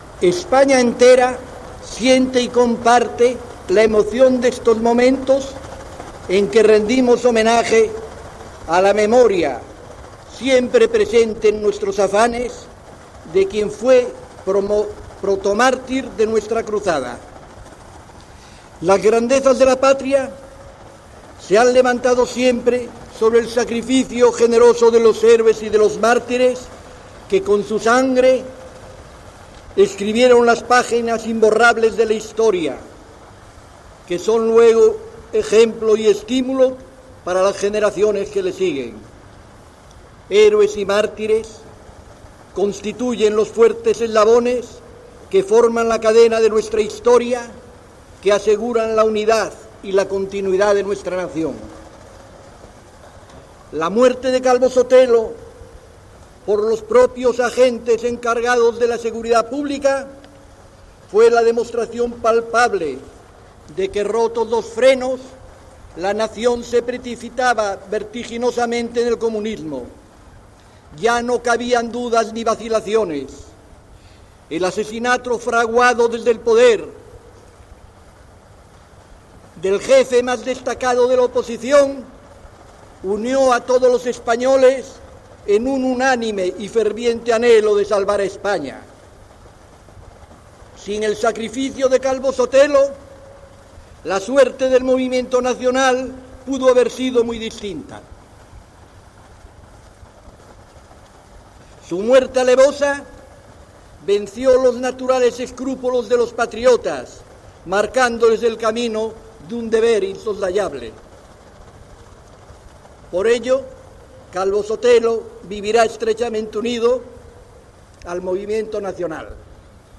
Discurs del "generalísimo" Francisco Franco en l'homenatge a José Calvo Sotelo
Informatiu